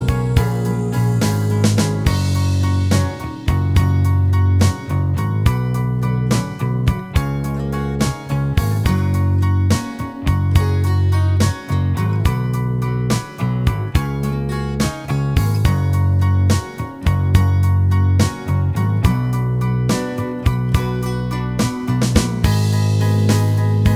Minus Lead Guitar Rock 3:36 Buy £1.50